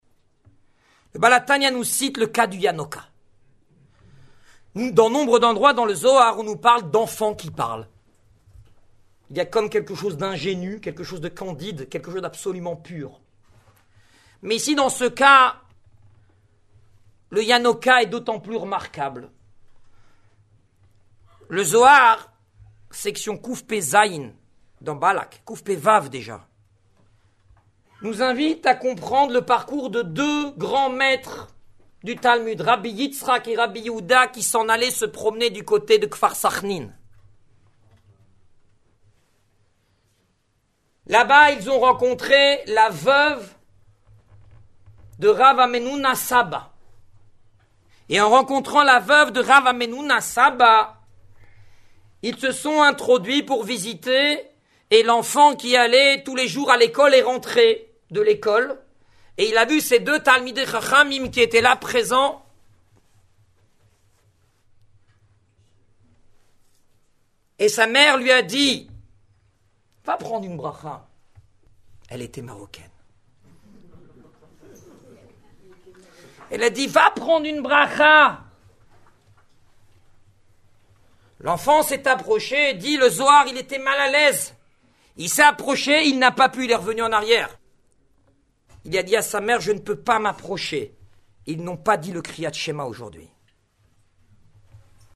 Ce Melavé Malka Vayéshev 5775 – 13 décembre 2014 au Igoud ‘Harédi à Paris débute avec des chants du Kahal